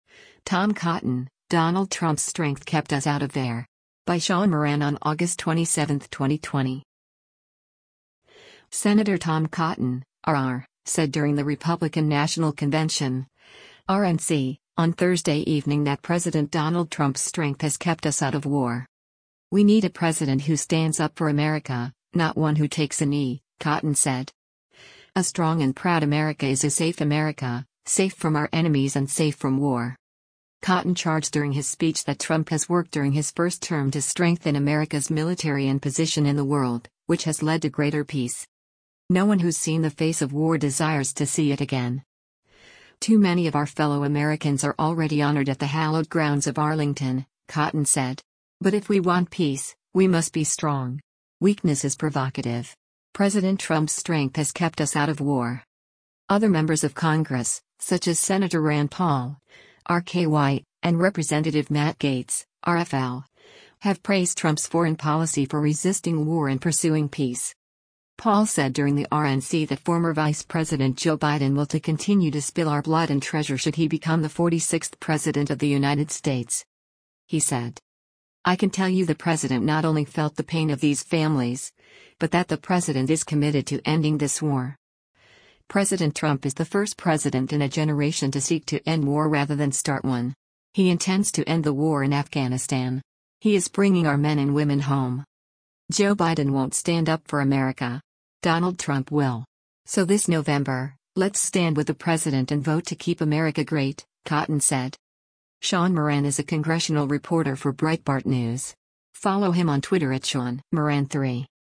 Sen. Tom Cotton (R-AR) said during the Republican National Convention (RNC) on Thursday evening that President Donald Trump’s “strength” has “kept us out of war.”